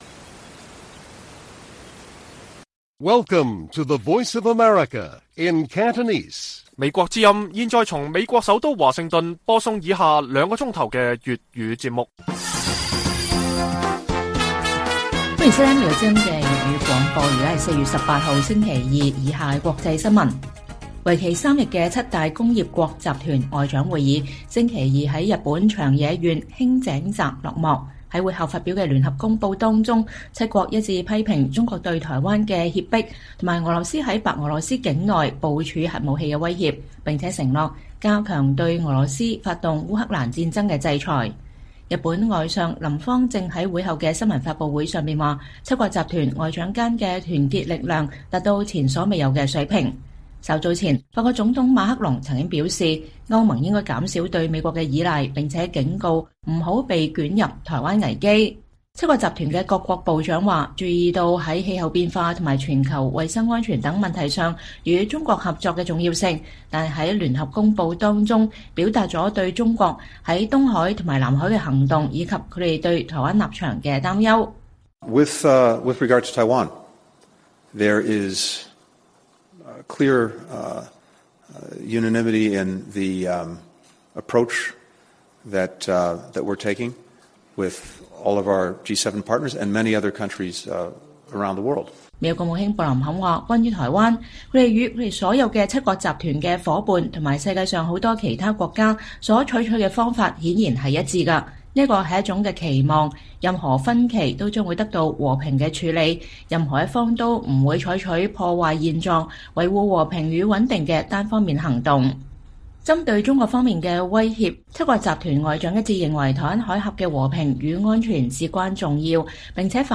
粵語新聞 晚上9-10點: 美國打擊在紐約的中國秘密海外警察站